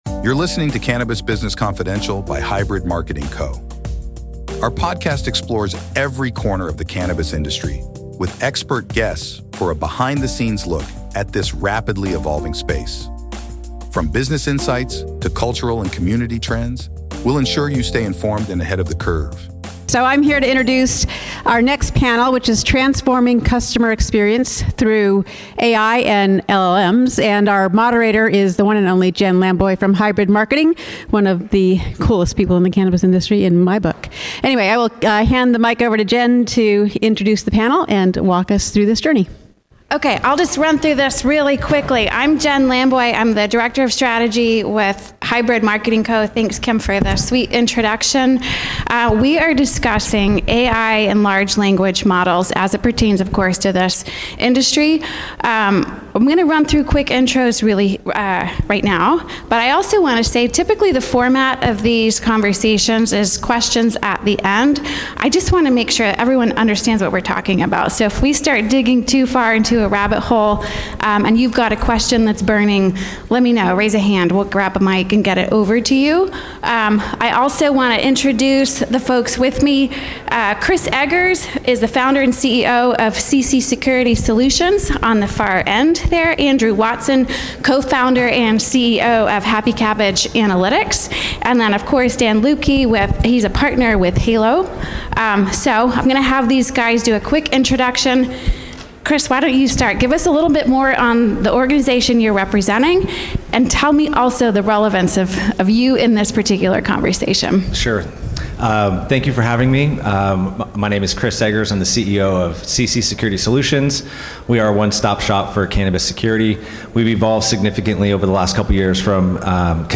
Conference session from MJ Unpacked St. Louis '24.